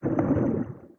cp-anim3-bubbles-fall.ogg